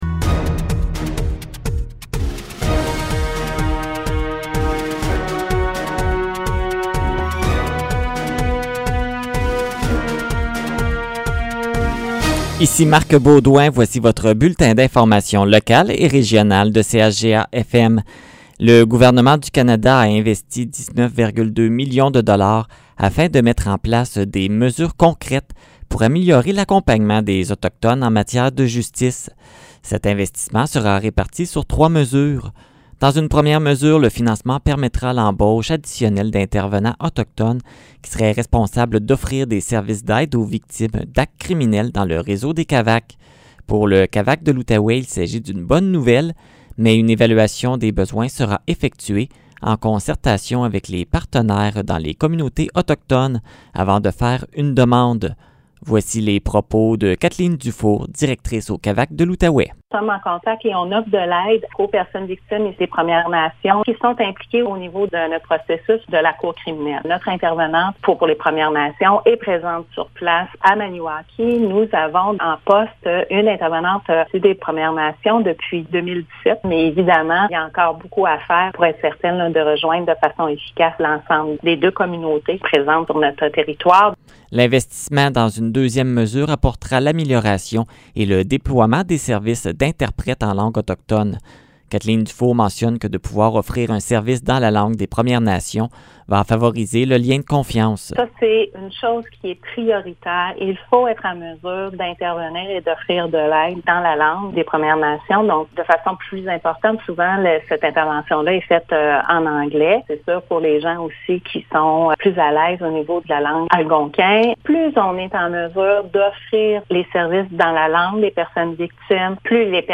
Nouvelles locales - 15 mars 2021 - 15 h